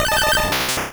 Cri de Triopikeur dans Pokémon Rouge et Bleu.